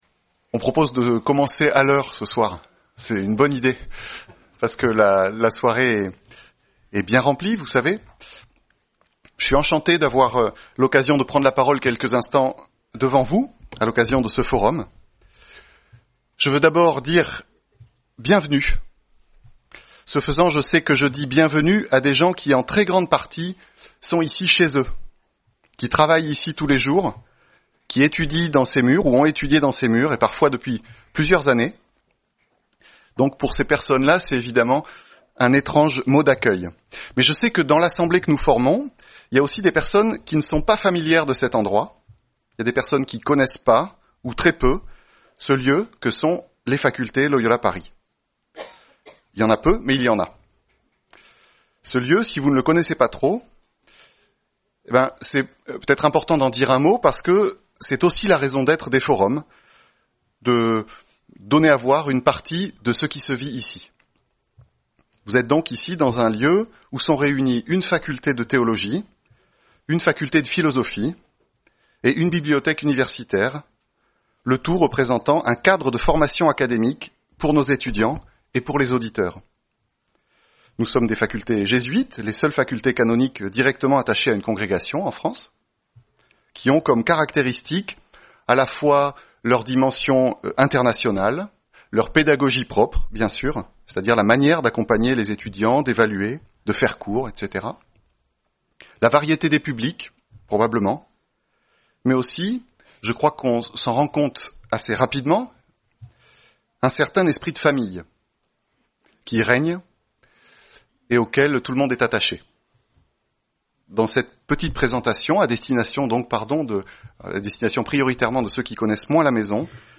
Le pape François a déclaré 2025 année jubilaire, avec pour thème « Pèlerins d’espérance ». A l’initiative des étudiants, c’est précisément ce thème que les Facultés Loyola Paris ont choisi d’honorer au cours du Forum de cette année.